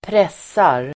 Uttal: [²pr'es:ar]